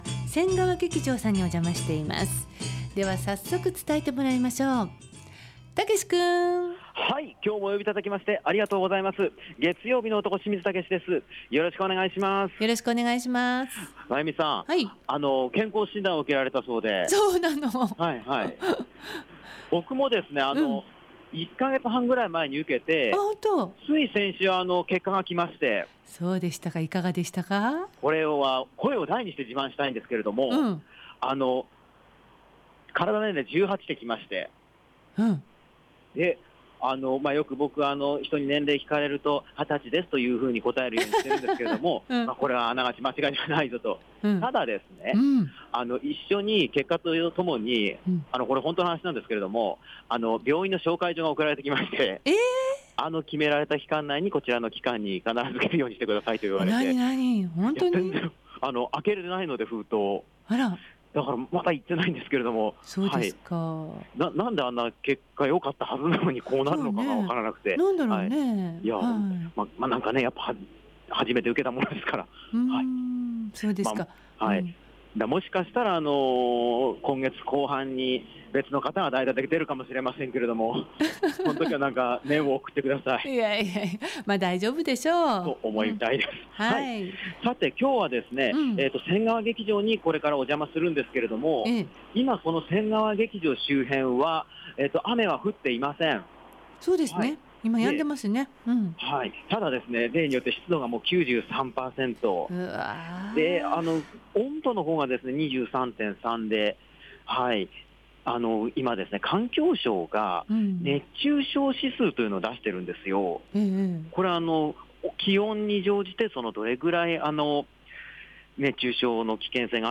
雨あがりのジメジメした曇り空の下からお届けした、本日の街角レポートはせんがわ劇場さんから『第10回せんがわ劇場 演劇コンクール』についてのレポートです！